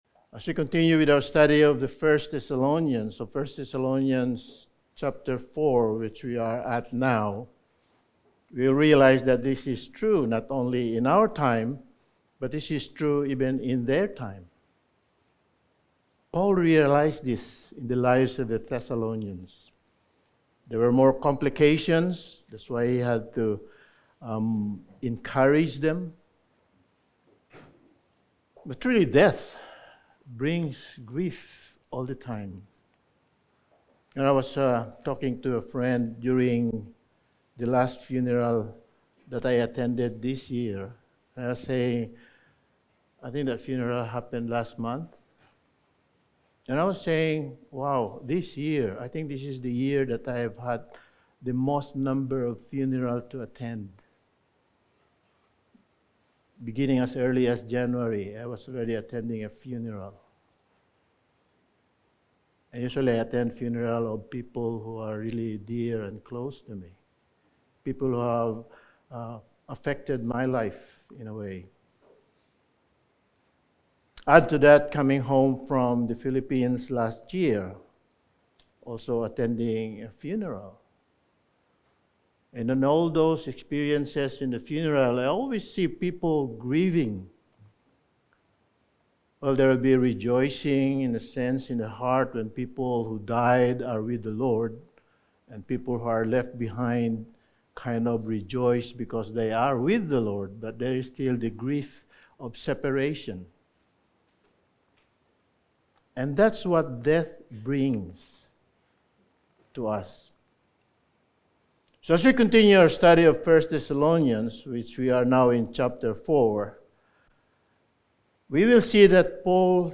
Sermon
Service Type: Sunday Morning Sermon